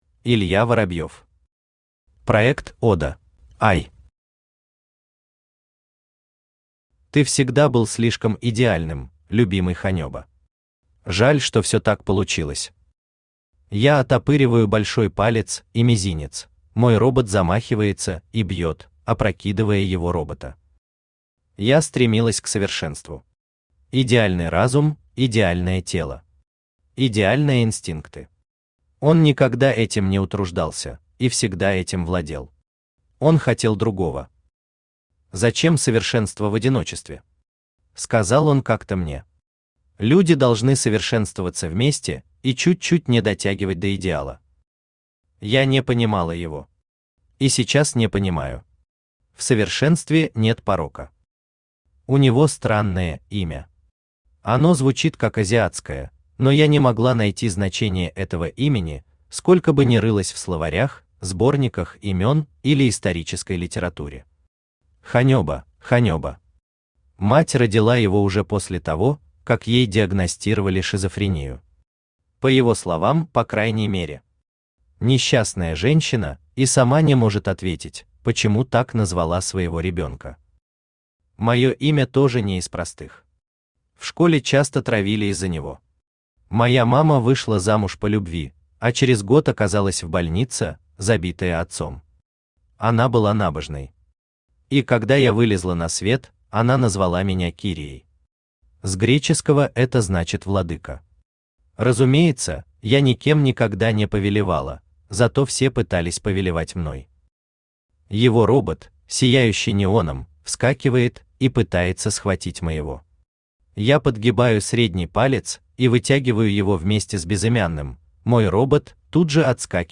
Аудиокнига Проект «Ода» | Библиотека аудиокниг
Aудиокнига Проект «Ода» Автор Илья Воробьев Читает аудиокнигу Авточтец ЛитРес.